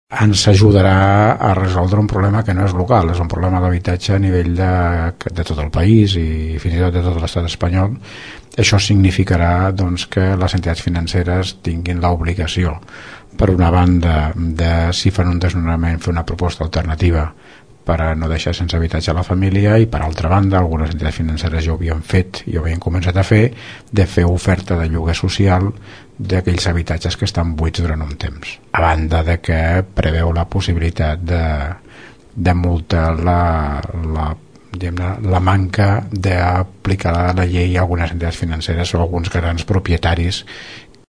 En aquest sentit, l’Alcalde de Tordera, Joan Carles Garcia, ha dit que la legislació ajudarà a solucionar un problema que afecta tot el país.